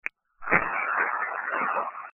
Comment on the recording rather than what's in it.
Saturday, June 16th 2007 - We investigated a family home in Franklin, Massachusetts